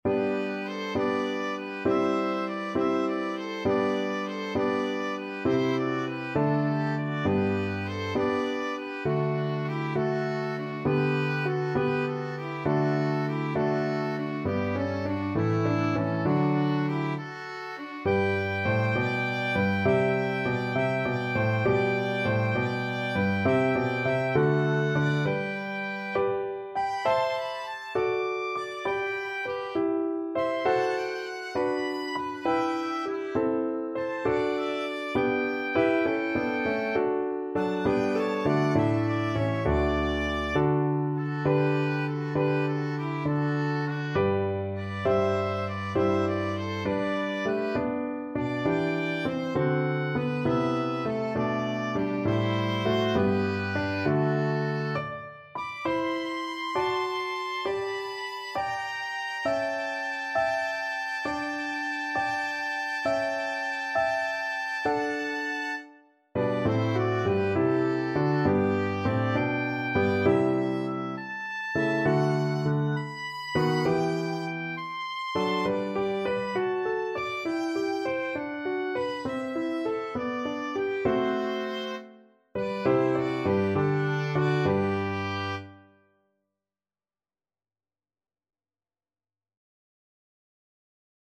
(with piano)
Largo
Classical (View more Classical Oboe-Violin Duet Music)